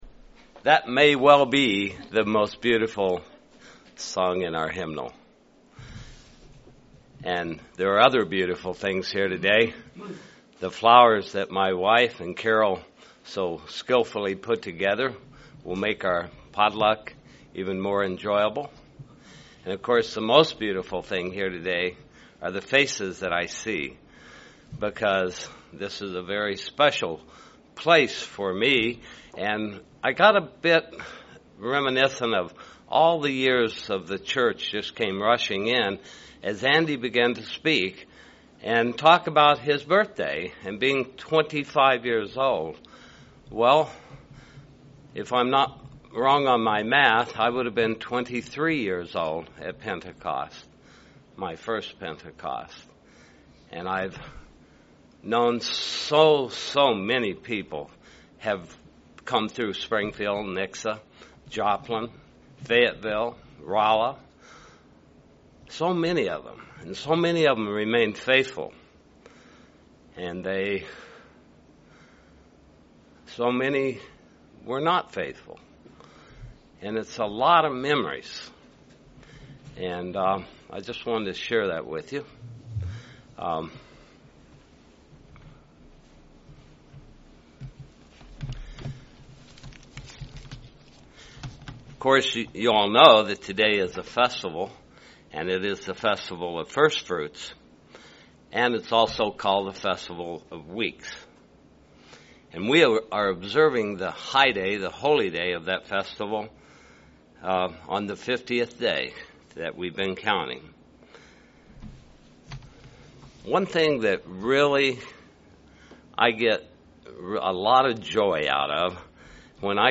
This sermon looks at the connection between God's firstfruits and His Holy Spirit.